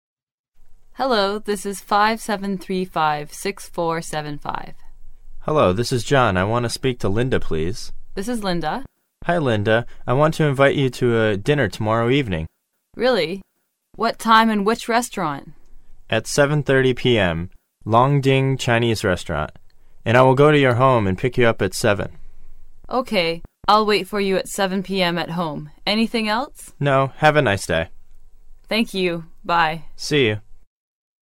英语口语900句 06.01.对话.1.接电话 听力文件下载—在线英语听力室